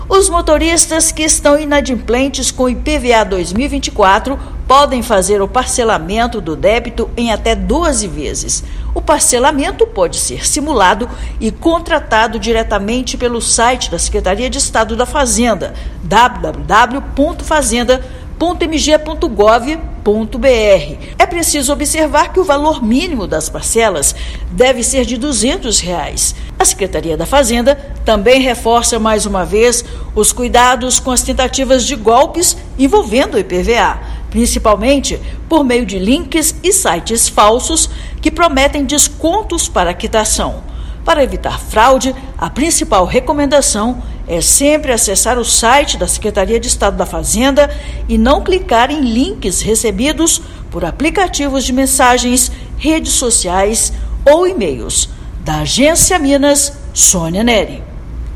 Em Minas, exigência do Certificado de Registro e Licenciamento do Veículo já está em vigor. Ouça matéria de rádio.